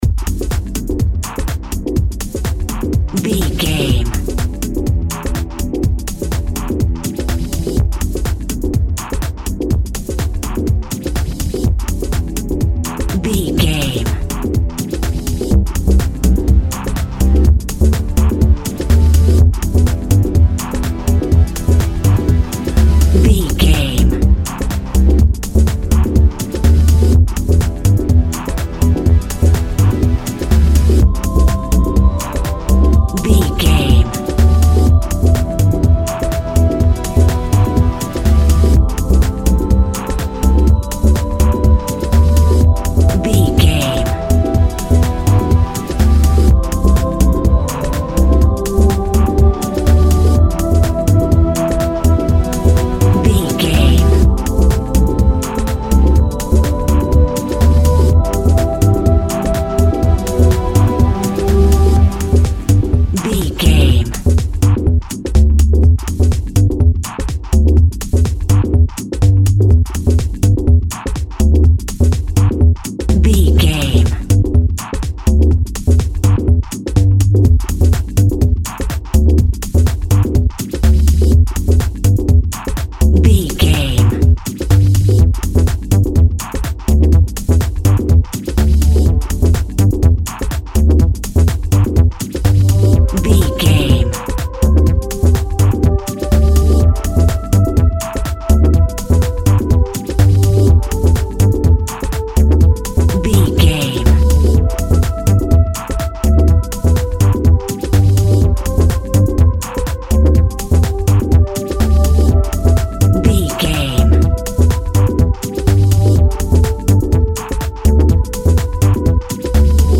Ionian/Major
indie pop
pop rock
sunshine pop music
drums
bass guitar
electric guitar
piano
hammond organ